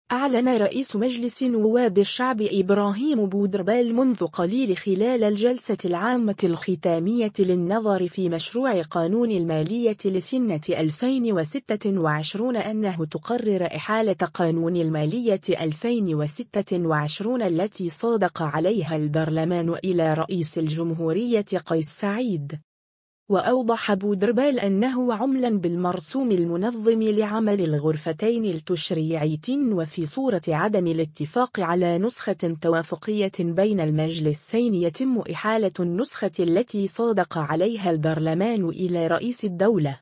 أعلن رئيس مجلس نواب الشعب ابراهيم بودربالة منذ قليل خلال الجلسة العامة الختامية للنظر في مشروع قانون المالية لسنة 2026 أنه تقرر إحالة قانون المالية 2026 التي صادق عليها البرلمان الى رئيس الجمهورية قيس سعيد.